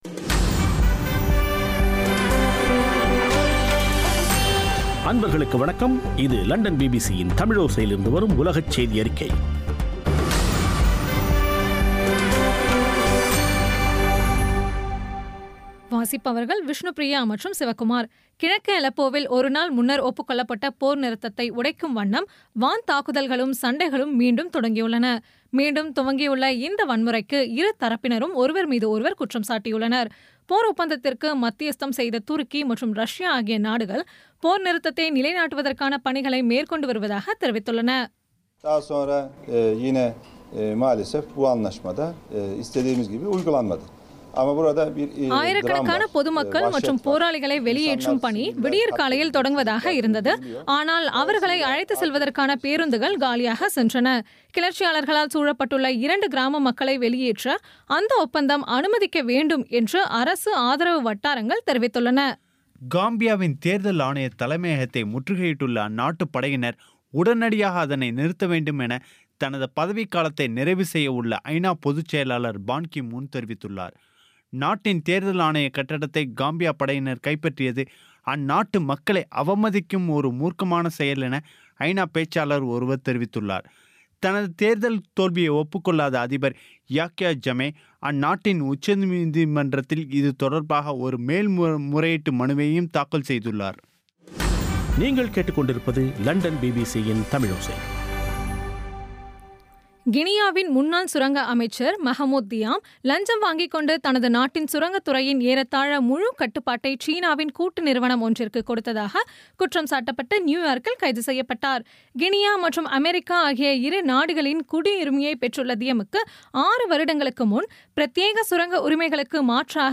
பிபிசி தமிழோசைசெய்தியறிக்கை (14/12/2016)